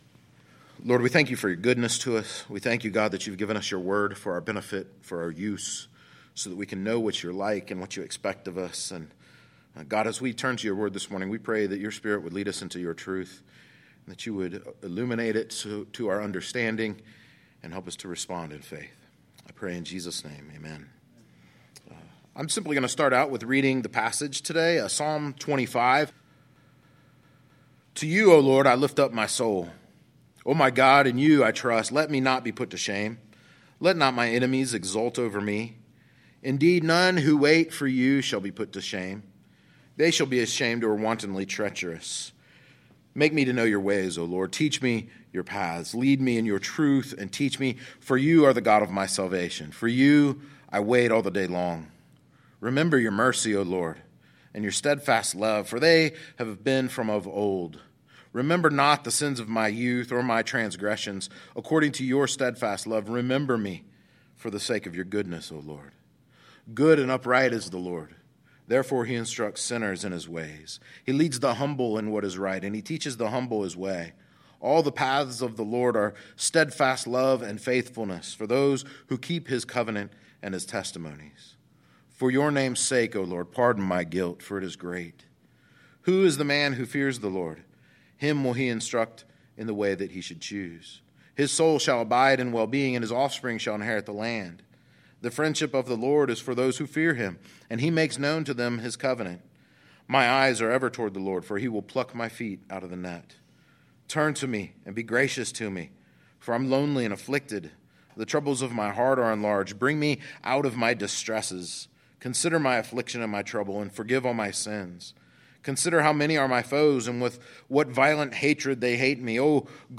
gods-guide-for-lifes-experiences-sermon-2-psalm-25.mp3